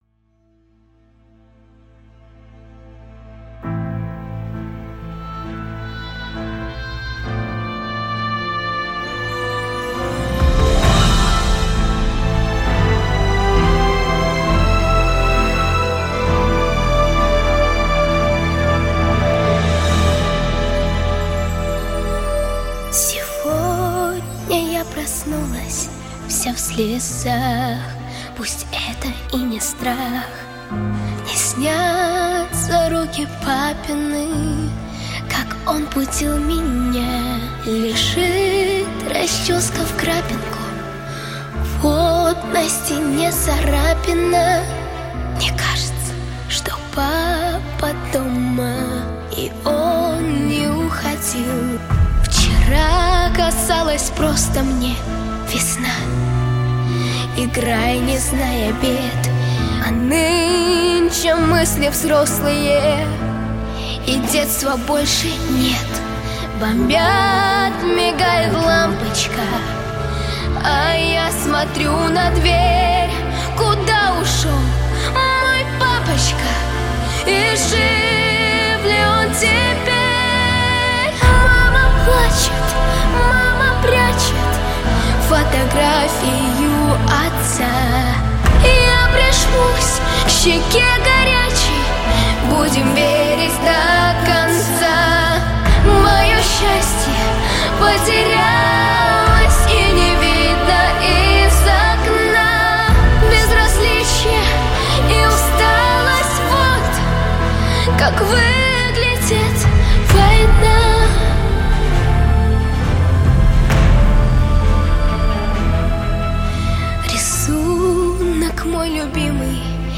• Категория: Детские песни / Песни про папу